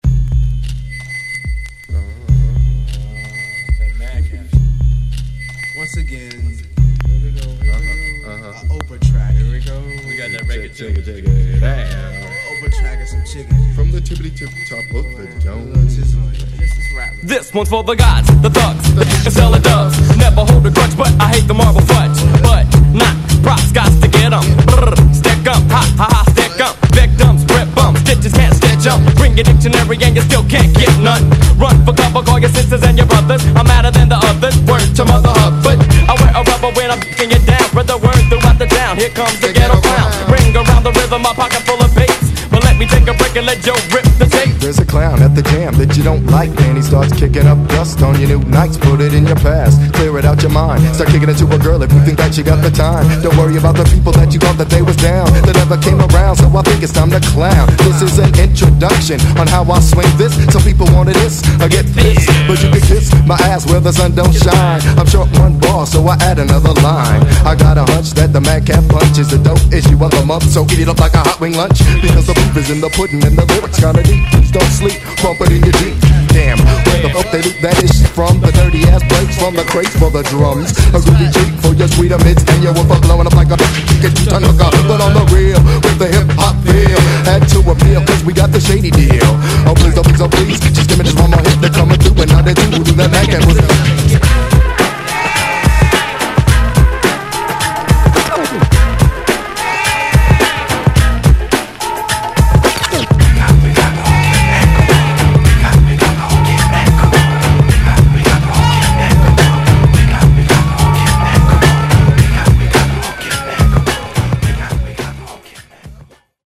ホーム HIP HOP 90's 12' & LP M
西海岸Jazzy New School名曲!!